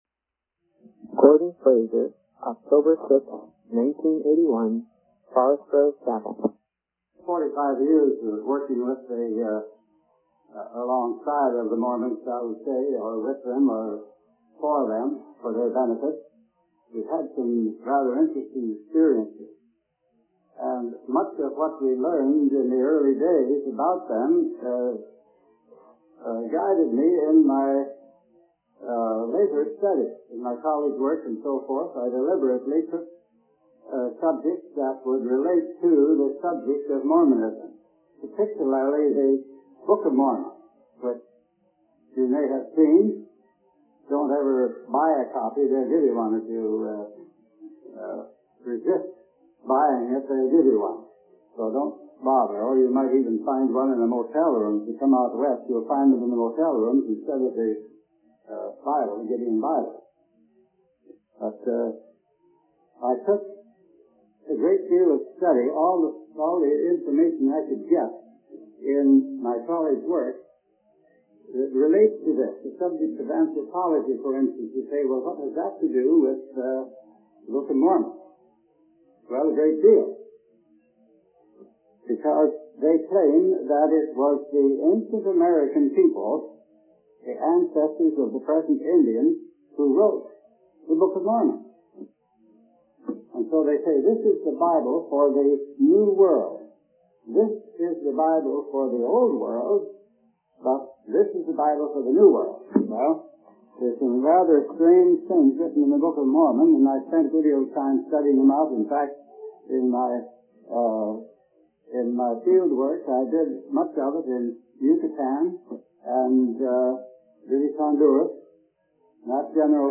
In this sermon transcript, the speaker begins by expressing gratitude for being born into a good family and having the opportunity to learn from his father.